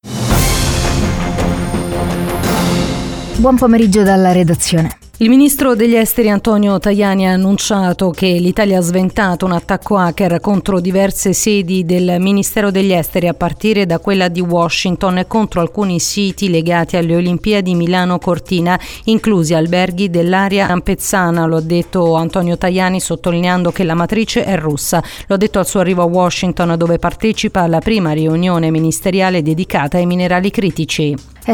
GIORNALE-RADIO-NAZIONALE.mp3